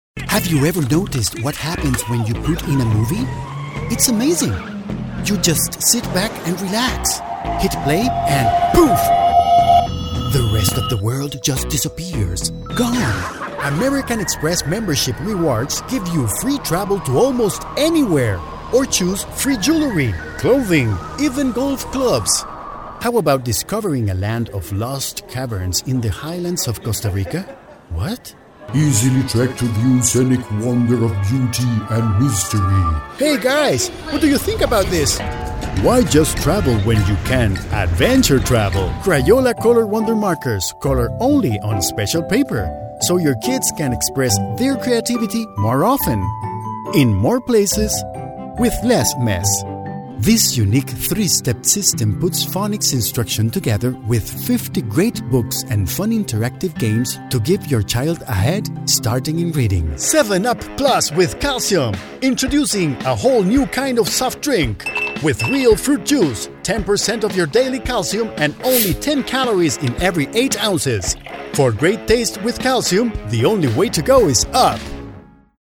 Spanish (Latin-America)
Commercial, Natural, Playful, Versatile, Corporate
Commercial